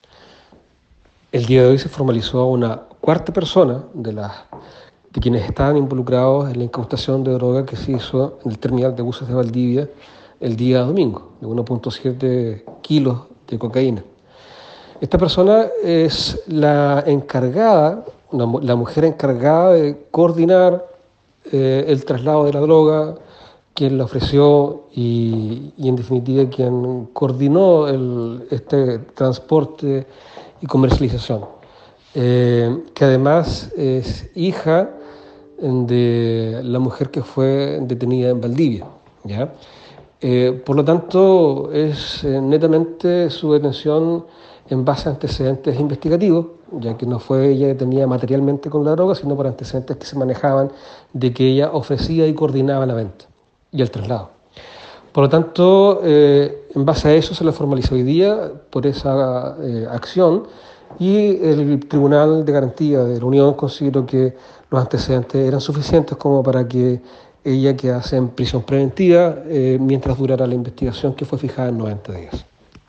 El  fiscal Carlos Bahamondes indicó sobre la formalización efectuada hoy por la Unidad Sacfi de la Fiscalía Regional de Los Ríos contra una mujer detenida en Santiago por el delito de tráfico de drogas.